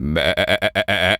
sheep_baa_bleat_08.wav